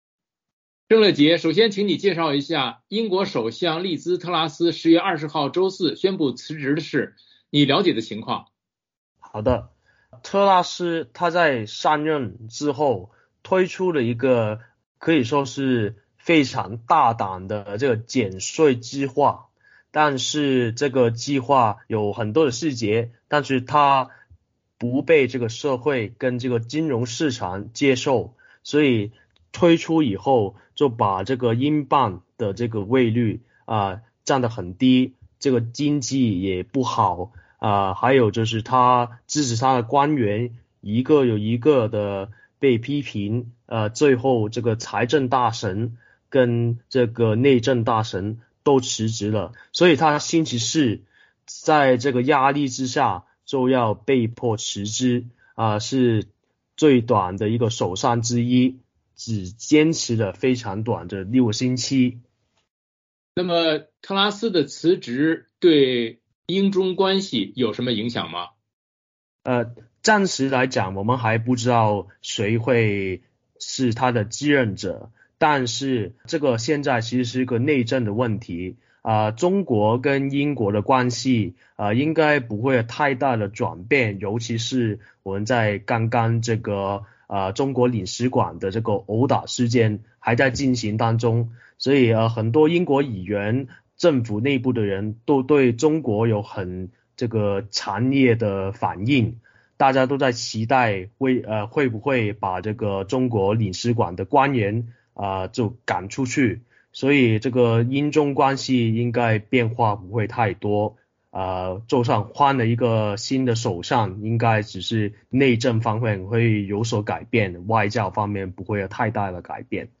VOA连线：记者连线：英国首相宣布辞职，英中关系不会有太大转变